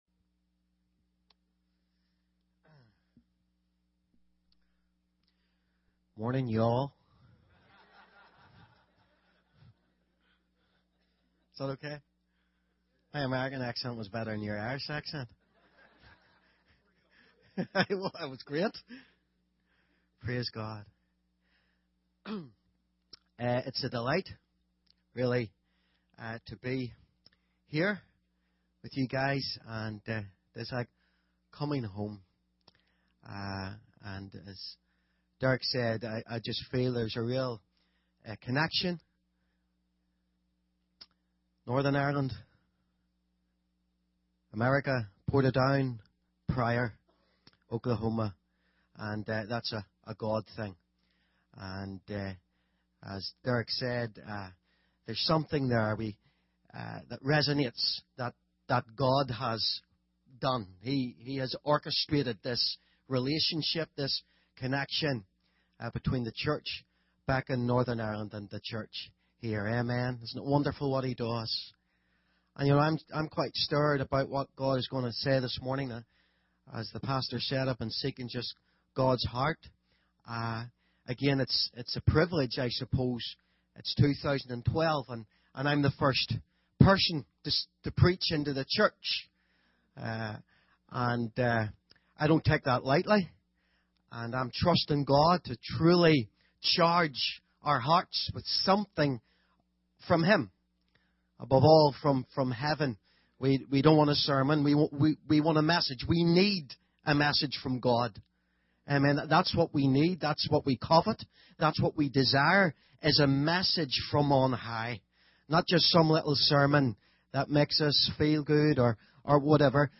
In this sermon, the preacher discusses the symbolism of the four species mentioned in Leviticus 23:39-41.